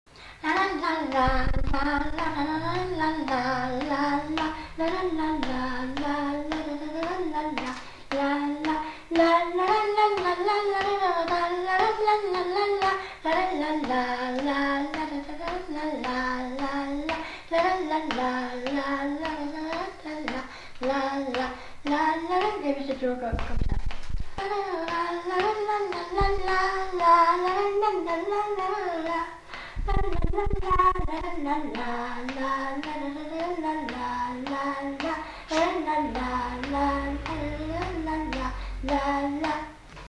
Aire culturelle : Gabardan
Genre : chant
Effectif : 1
Type de voix : voix de femme
Production du son : fredonné
Danse : rondeau